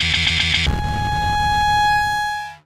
borg_deathsound.ogg